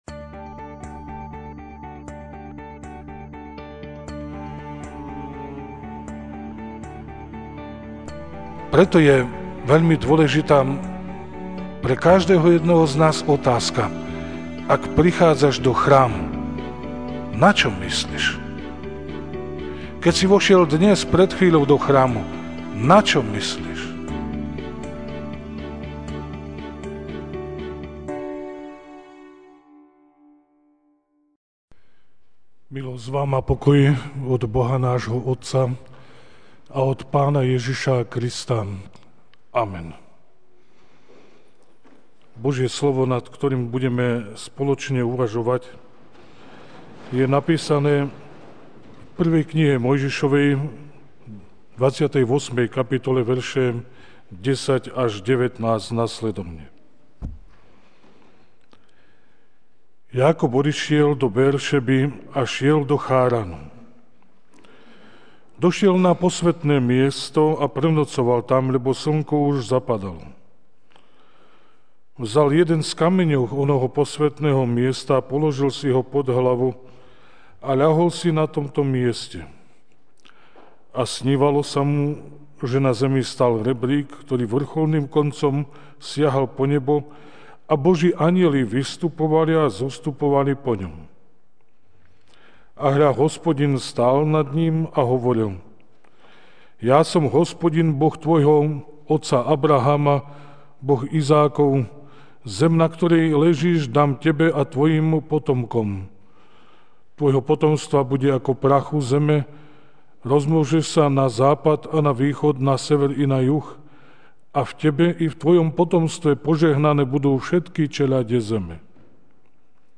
okt 09, 2016 Pamiatka posvätenia kostola MP3 SUBSCRIBE on iTunes(Podcast) Notes Sermons in this Series Ranná kázeň: (1M 28, 10-19) Jákob odišiel z Beér-Šeby a šiel do Cháránu.